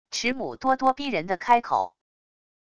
池母咄咄逼人的开口wav音频